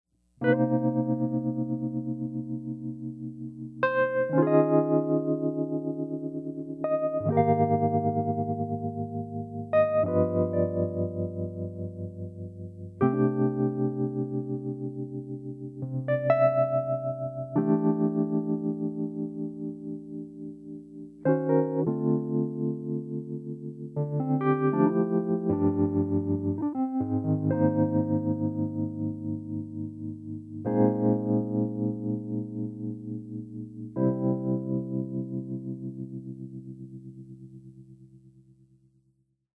Wurlitzer 106P Tremolo Demo:
106P-trem.mp3